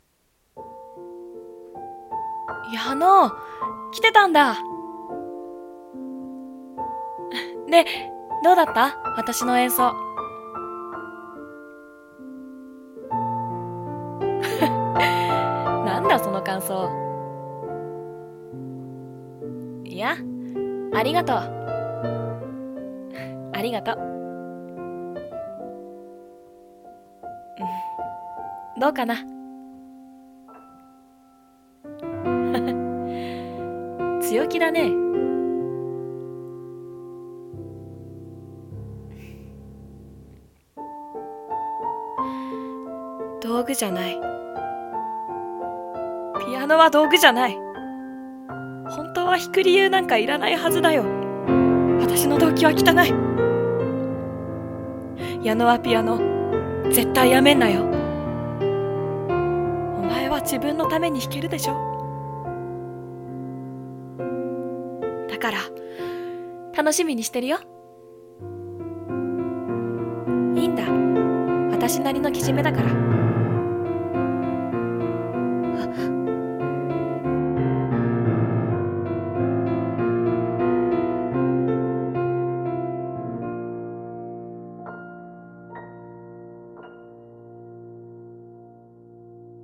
【声劇】僕がピアノを弾く理由